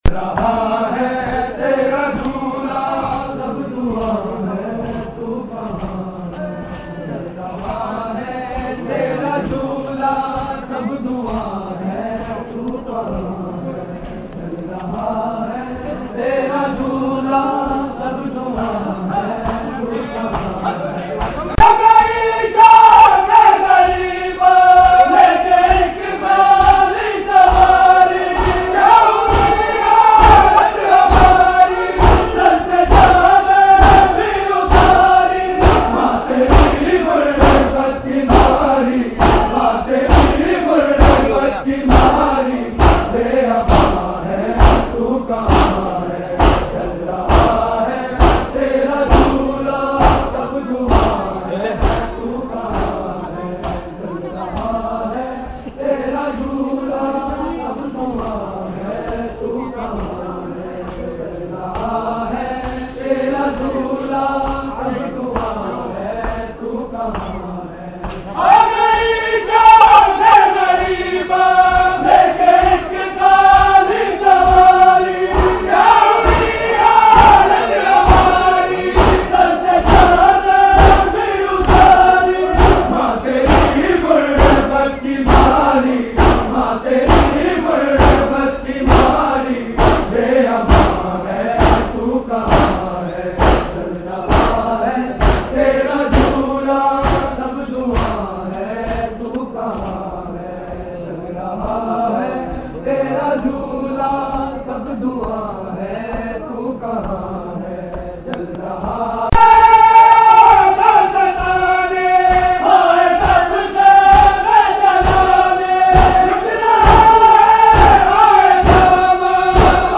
Recording Type: Live